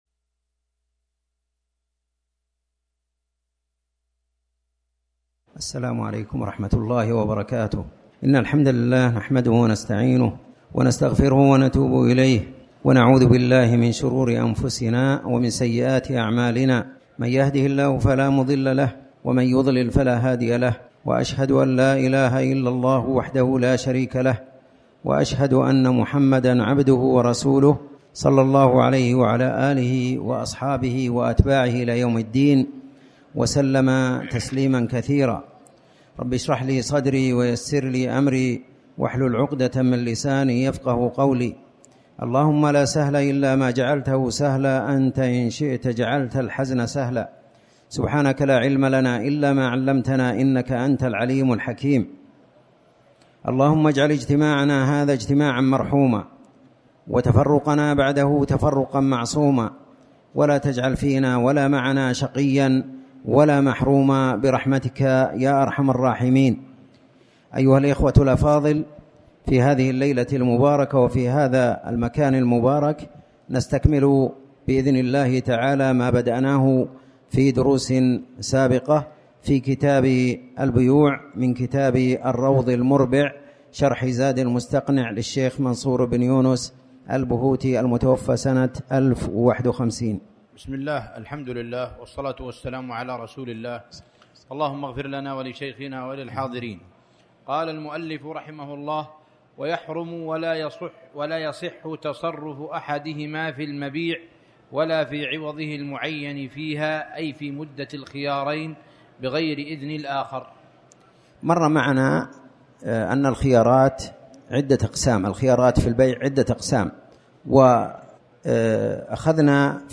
تاريخ النشر ١٥ شعبان ١٤٣٩ هـ المكان: المسجد الحرام الشيخ